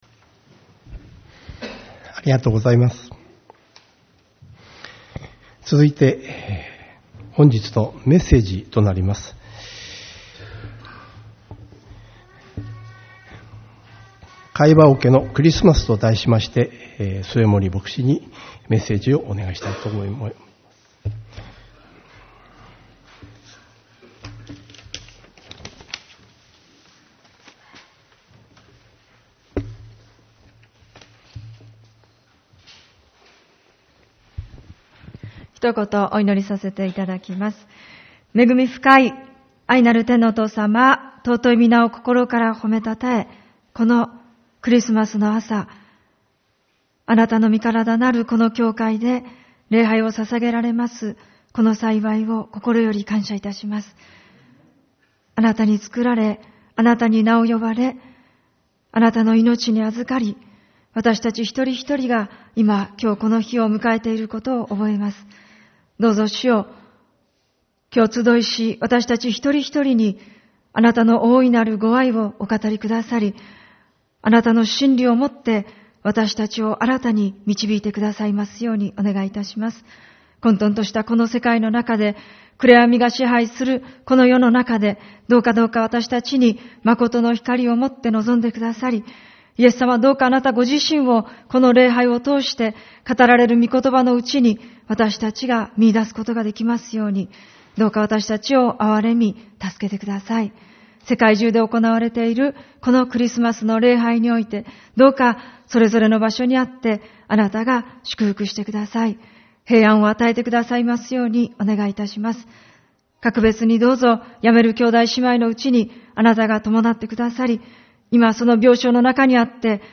主日礼拝 「飼い葉桶のクリスマス」